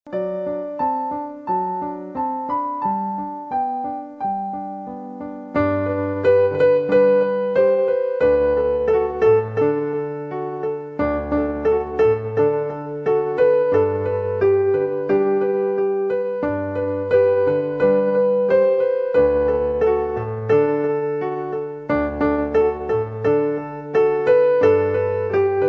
Home > Lullabies
Sheet Music or Piano Solo Download
The piano solo track in MP3 format is also available below.
Downloadable Instrumental Track